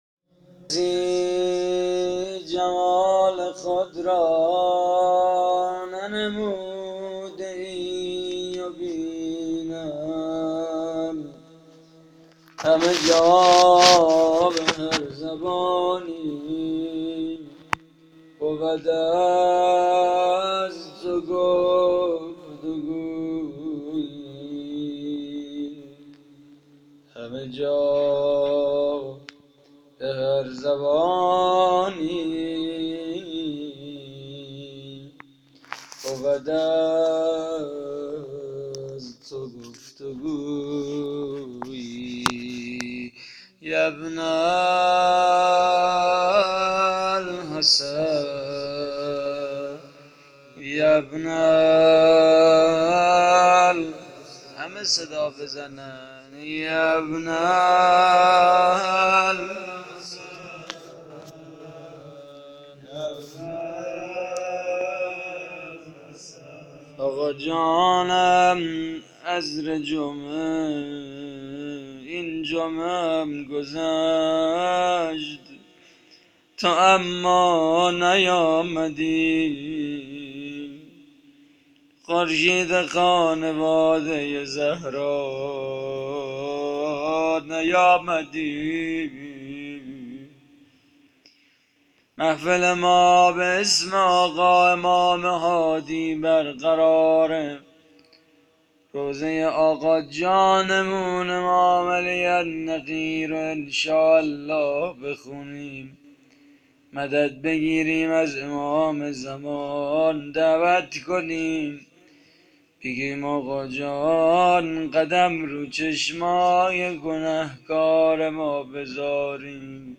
روضه خانگی عزاداری شهادت امام هادی (ع)؛ 27 فروردین 95